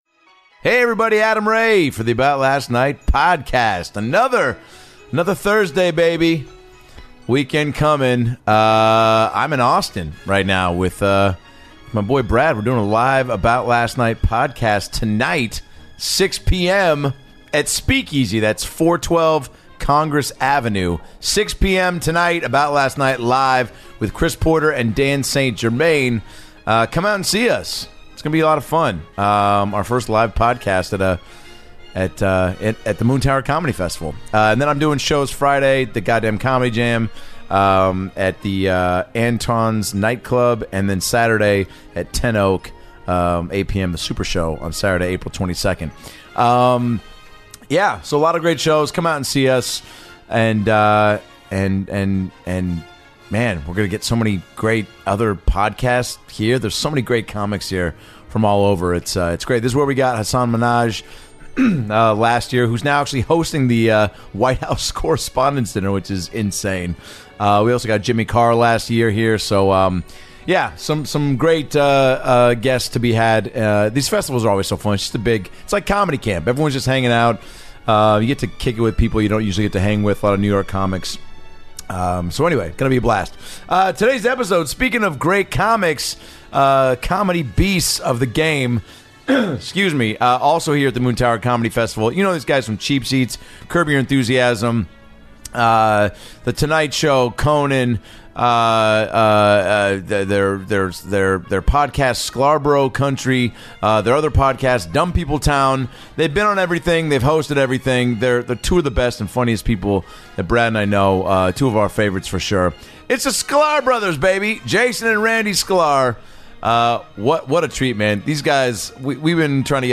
The Sklar Bros. stop by to chat ping pong, their journey together into comedy, their hilarious show Cheap Seats, and re-watching classic movies with their kids. It's an instant all timer, with two of the best comedians in the business with stories for days.